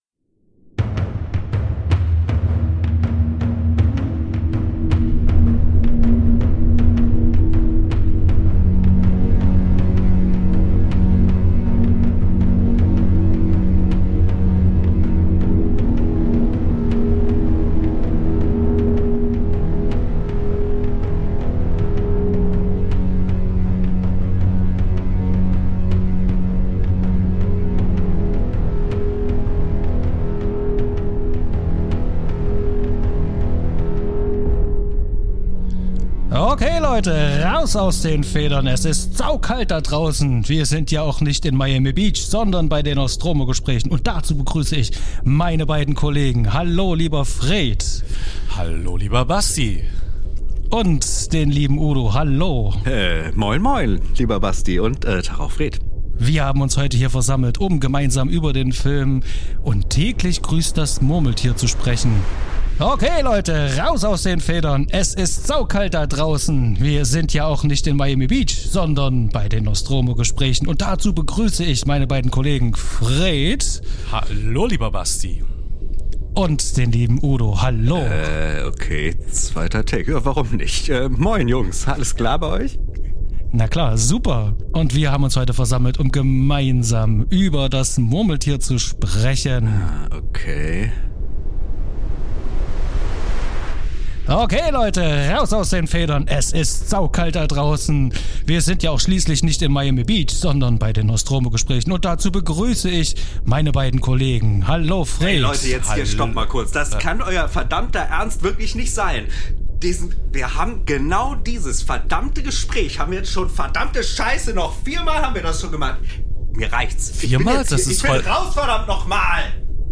mal im Duo, mal im Trio und ab und an mit erlesenen GästInnen